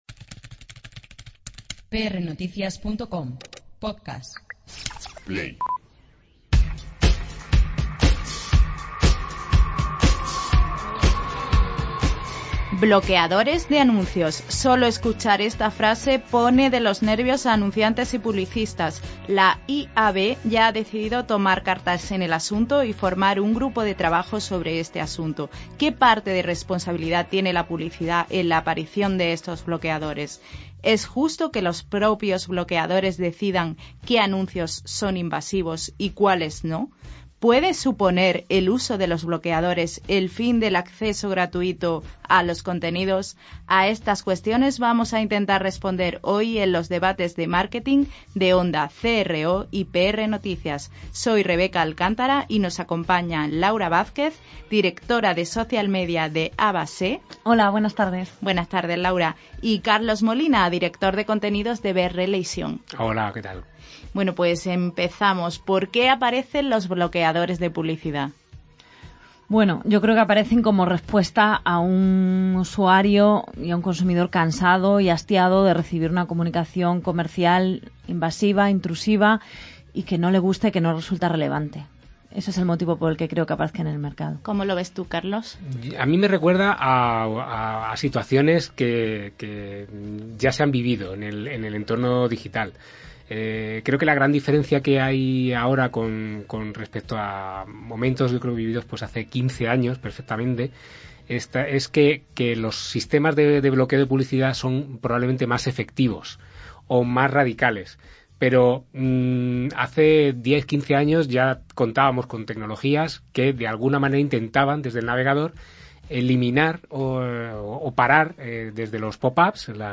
PR_DEBATE_BLOQ_ANUNCIOS.mp3